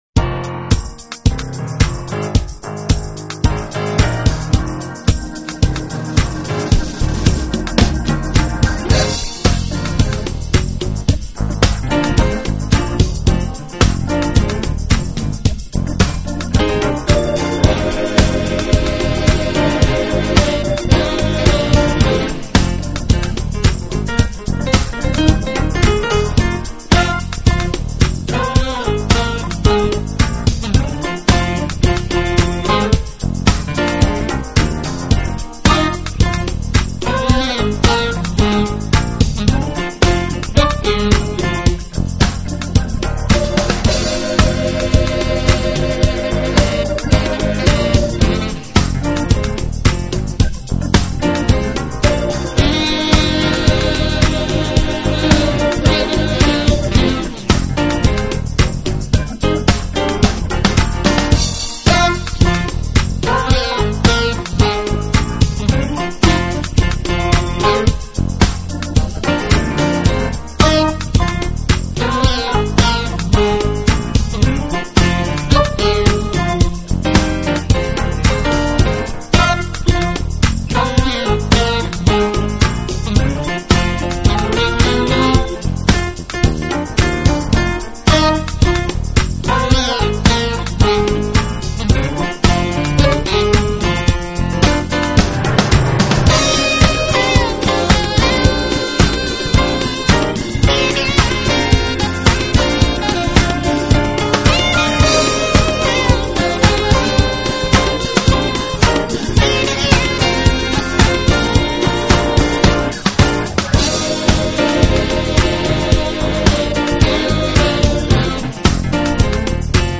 【爵士钢琴】
现代爵士钢琴家